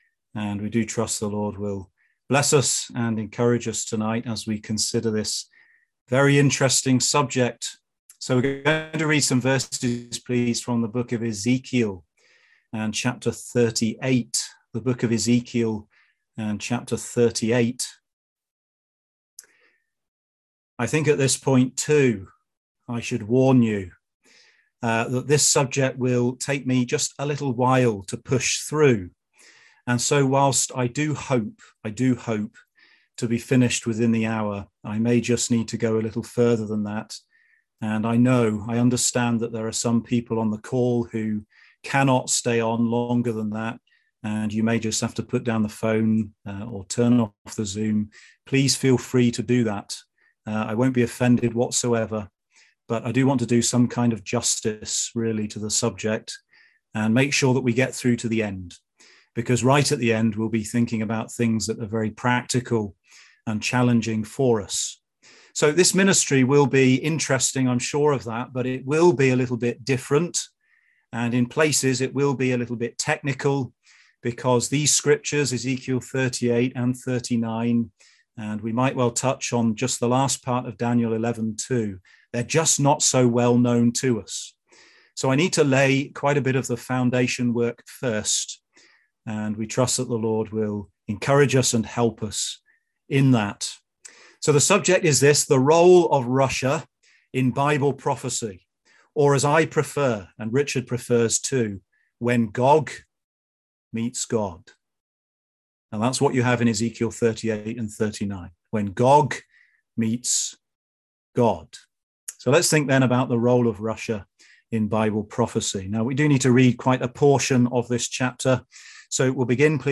Also given as ministry at Caerphilly on 12th March 2022 (see downloadable audio file below).